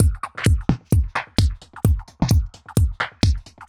Index of /musicradar/uk-garage-samples/130bpm Lines n Loops/Beats